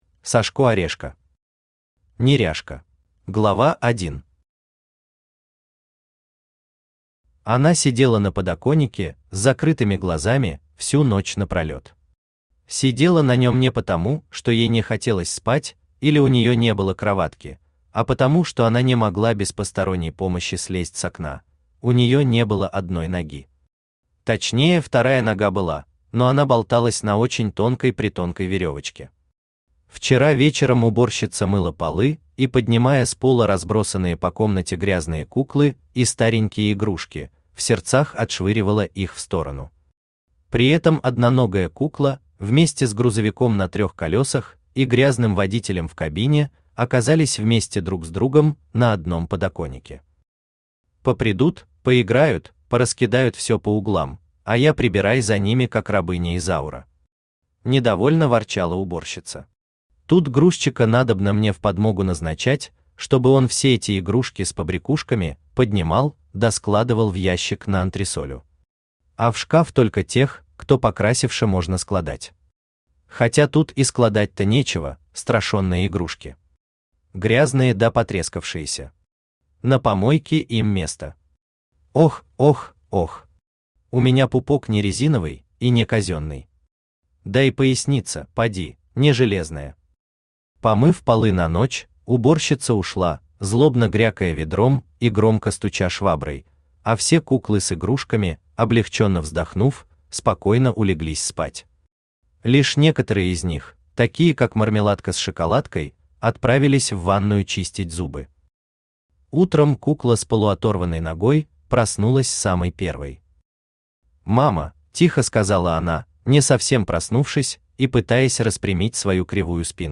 Aудиокнига Неряшка Автор Сашко Орешко Читает аудиокнигу Авточтец ЛитРес.